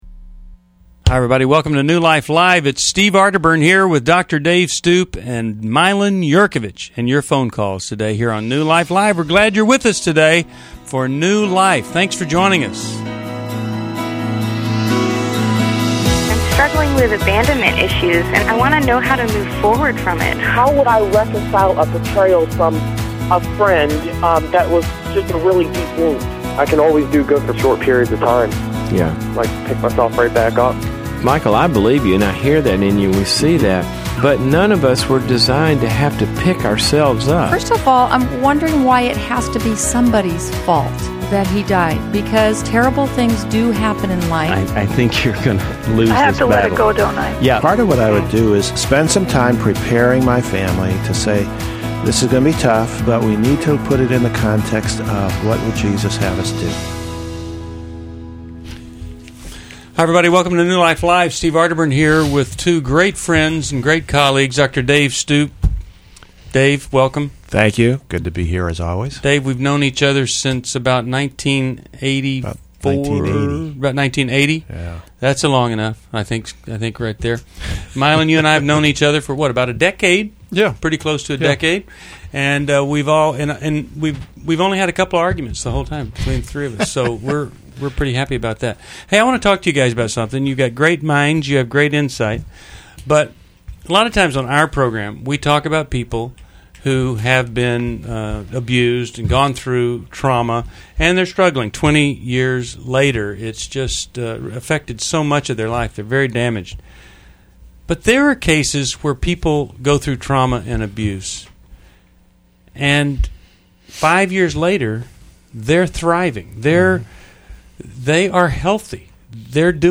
Explore healing from infidelity, marital struggles, and personal challenges in New Life Live: July 15, 2011. Join experts as they tackle real caller dilemmas.